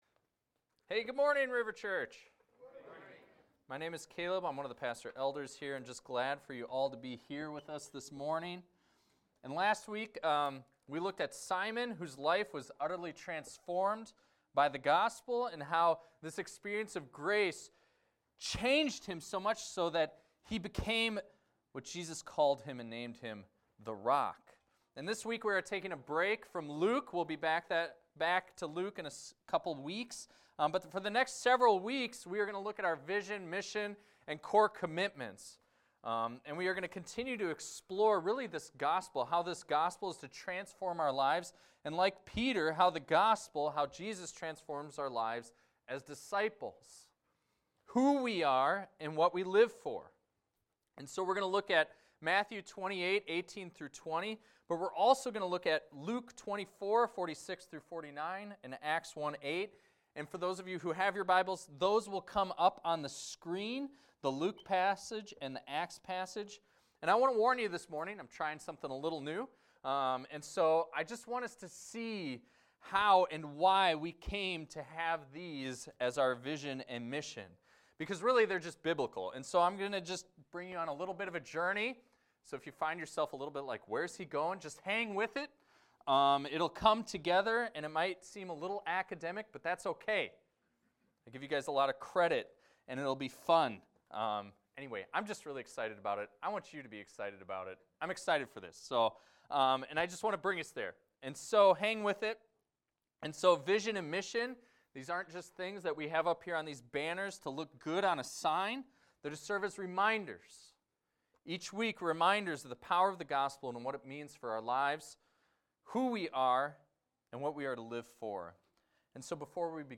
This is a recording of a sermon titled, "Magnify Jesus."